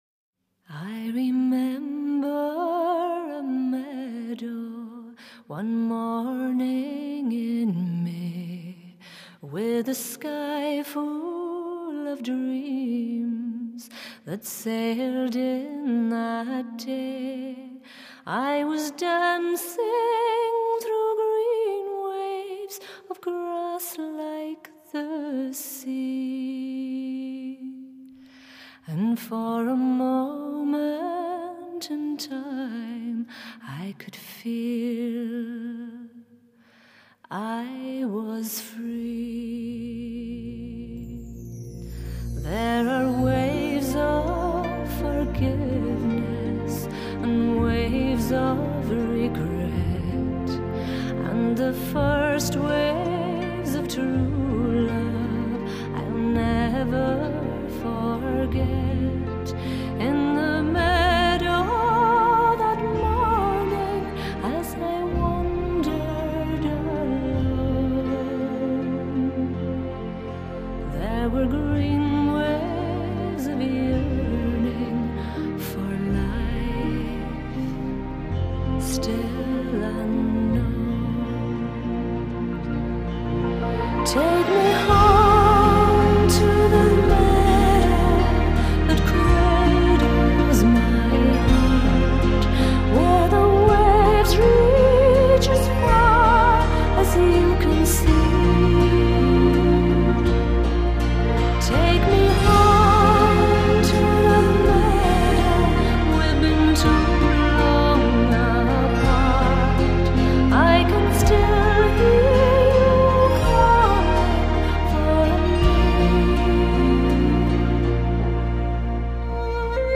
低沉耐人寻味专辑延续了小提琴和钢琴在乐曲中的主导地位和对古朴叙事歌谣的表现力
融合了爱尔兰空灵飘渺的乐风，挪威民族音乐及古典音乐
乐曲恬静深远，自然流畅。